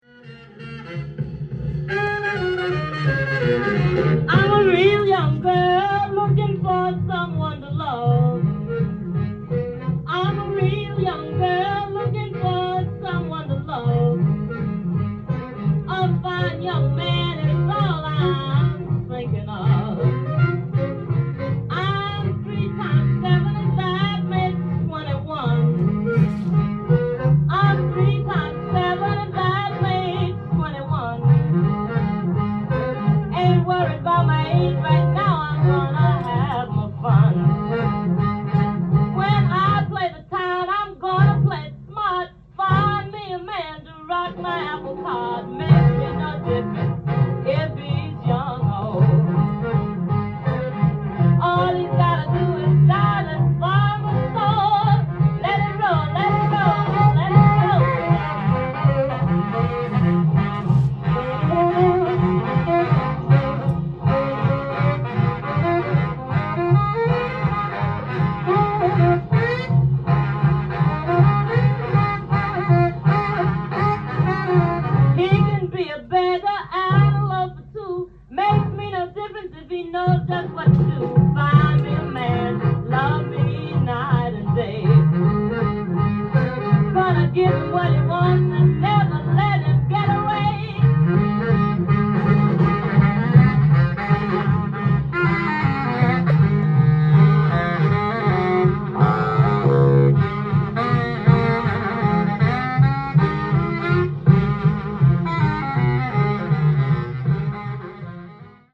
店頭で録音した音源の為、多少の外部音や音質の悪さはございますが、サンプルとしてご視聴ください。
ホットなRockin R&Bサックスサウンドが楽します！